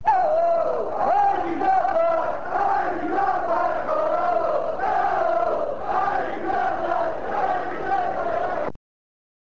This page contains, for the moment, chants from the last game of the season at Easter Road on 8th May 1999, along with Alex McLeish's speech after the game.